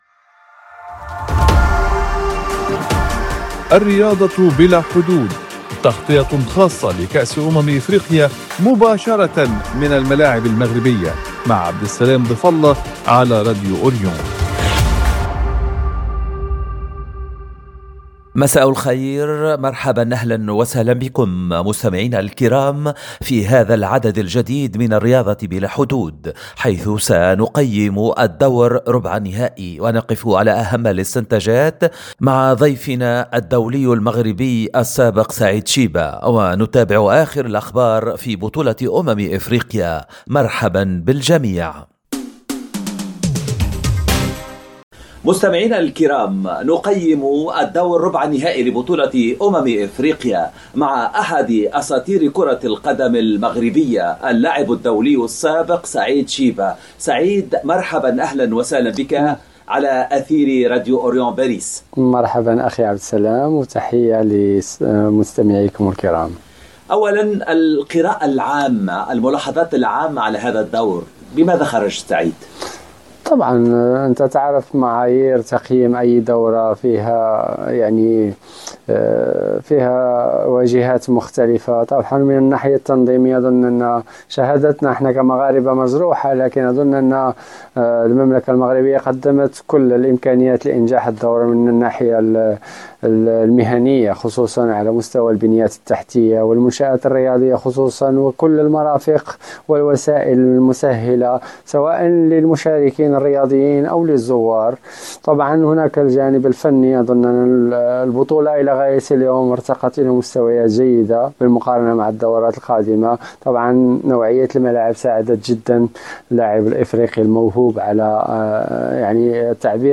في قراءة فنية معمّقة عبر أثير إذاعة “أوريون“، قدّم المحلل الرياضي الدولي ولاعب المنتخب المغربي السابق سعيد شيبة تشريحًا دقيقًا لموازين القوى في دور المربع الذهبي لبطولة كأس أمم أفريقيا، مسلطًا الضوء على مكامن القوة والضعف لدى المنتخبات الأربعة المتبقية، ومستشرفًا ملامح ما وصفه بـ”النهائي الحلم” بين المغرب والسنغال.